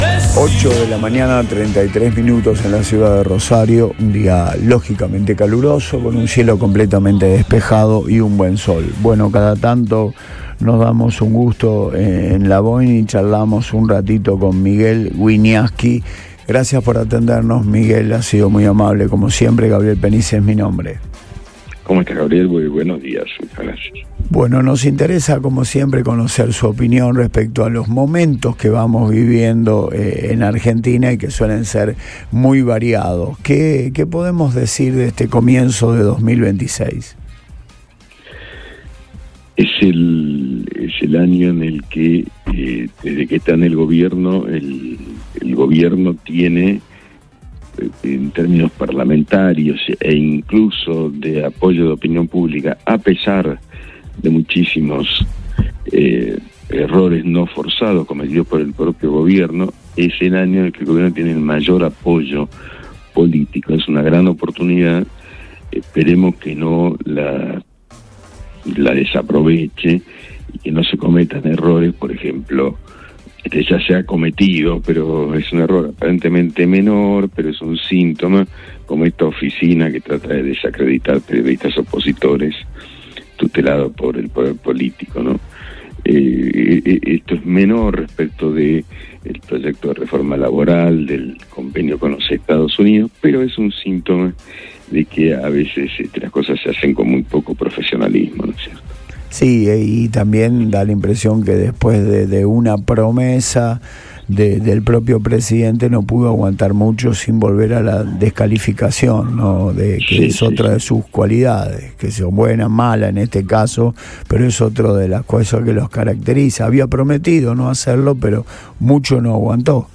En una charla con Antes de Todo, el periodista destacó que, pese al fuerte apoyo político que mantiene el Gobierno en este inicio de 2026, persisten síntomas de intolerancia.
En diálogo con Radio Boing, el intelectual analizó el escenario nacional en este 10 de febrero, un año que definió como “la gran oportunidad” del Gobierno debido al respaldo parlamentario y de opinión pública que ostenta, aunque advirtió sobre los “errores no forzados” que empañan la gestión.